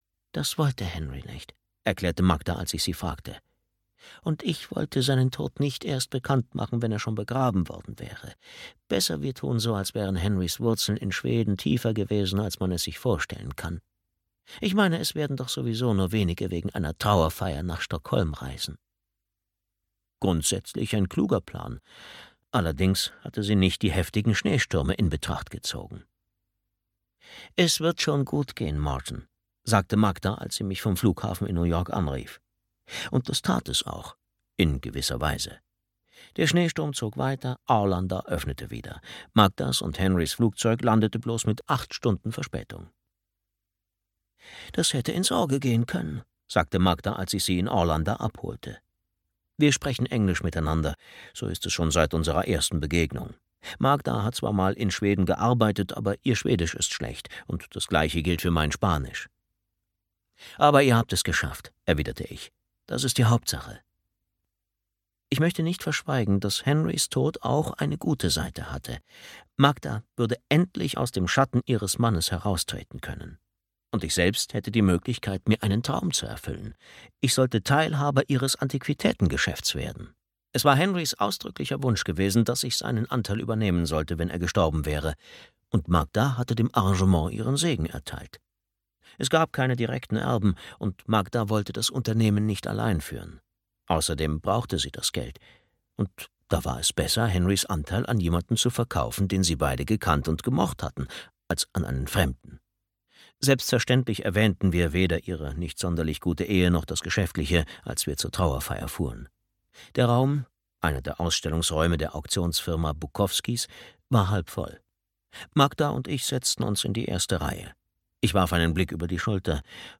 Blutsfreunde (DE) audiokniha
Ukázka z knihy